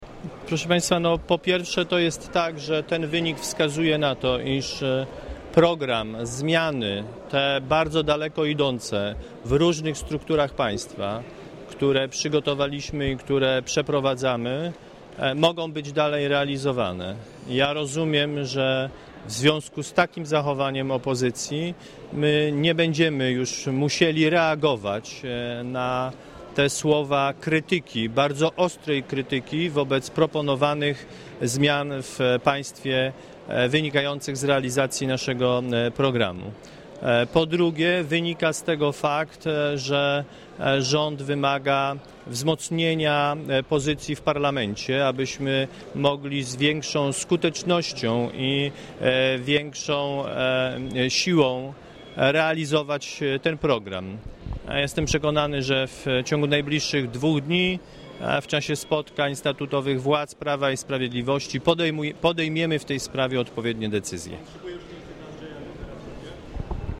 Posłuchaj komentarza premiera Kazimierza Marcinkiewicza
Marcinkiewicz powiedział dziennikarzom w Sejmie, że po czwartkowym głosowaniu nad samorozwiązaniem Sejmu "rząd wymaga wzmocnienia pozycji w parlamencie".